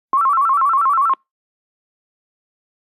电话 " 电话铃声，数字，4
描述：无线家庭电话，3音，干准备循环 麦克风：AKG C 1000S（电话包中有不同的版本和速度。）
标签： 铃声 手机 电话 通讯
声道立体声